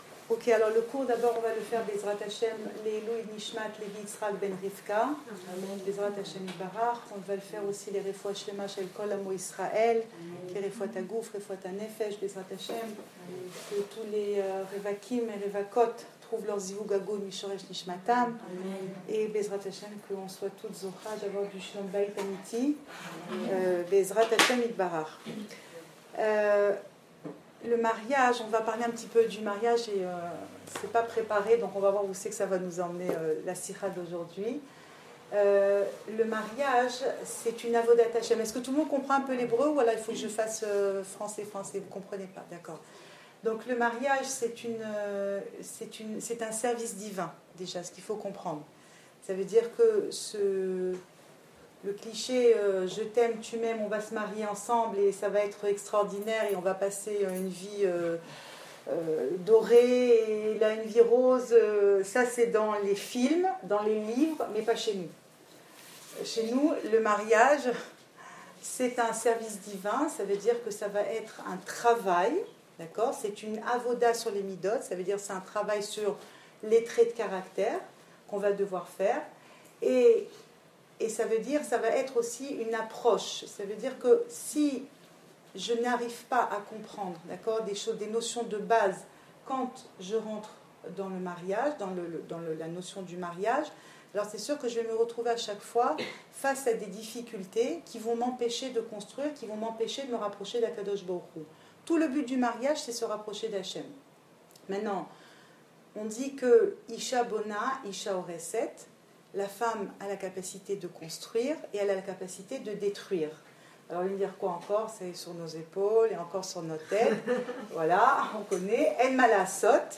Cours audio
Enregistré à Batyam